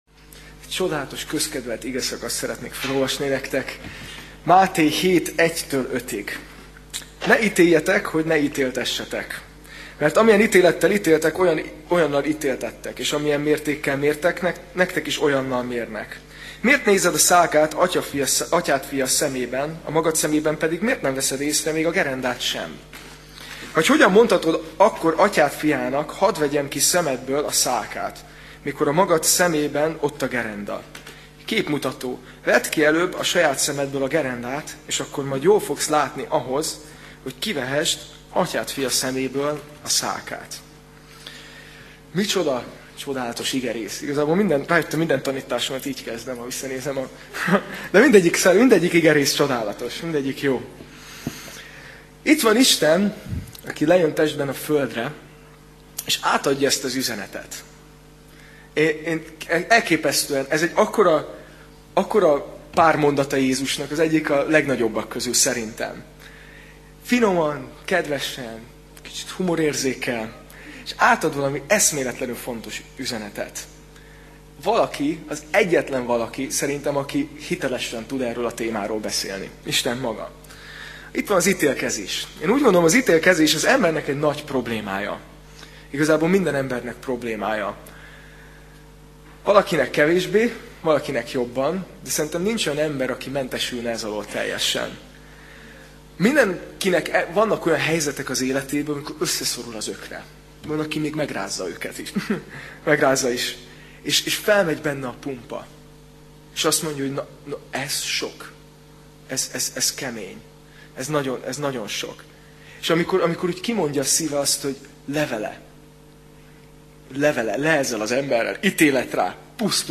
Tematikus tanítás Alkalom: Vasárnap Este